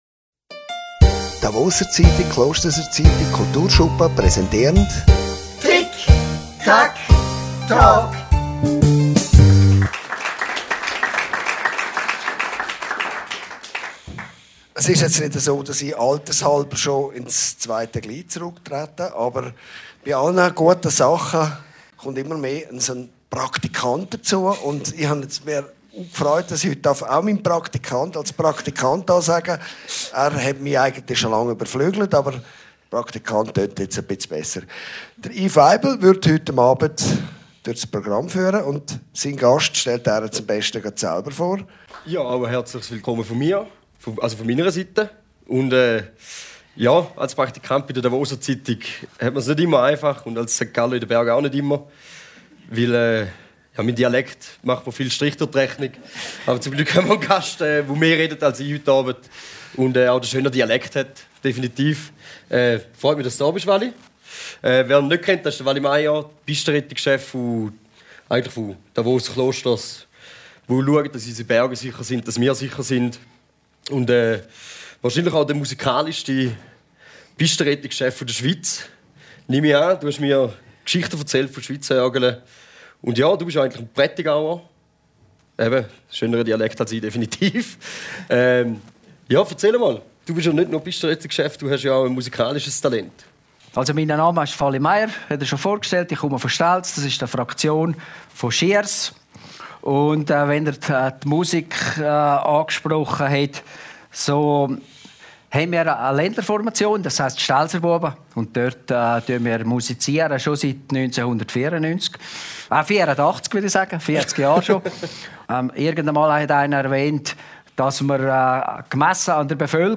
Dabei werden heitere wie ernste Themen, Spannendes und Nebensächliches, auf lockere Art und Weise thematisiert. Und das Publikum kann Fragen stellen.